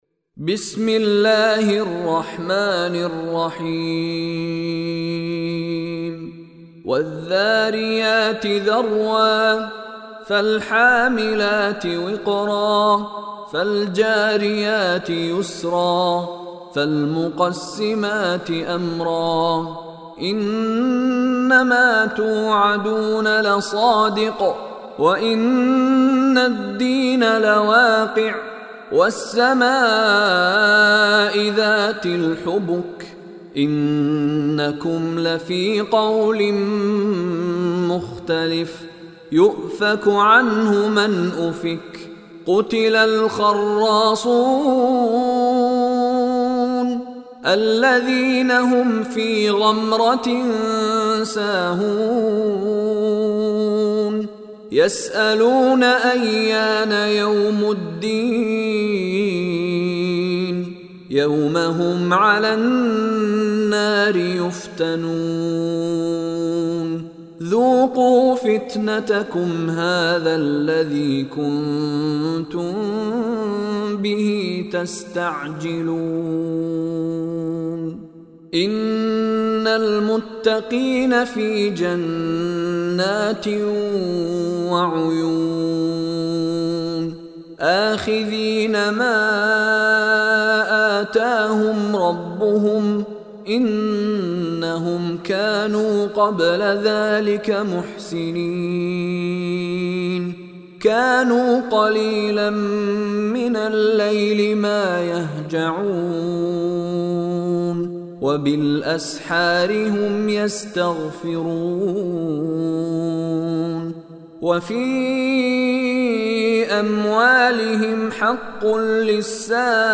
Surah Adh Dhariyat Recitation by Mishary Rashid
Surah Adh Dhariyat is 51 chapter or Surah of Holy Quran. Listen online and download mp3 audio recitation / tilawat of Surah Adh Dhariyat in the beautiful voice of Mishary Rashid Alafasy.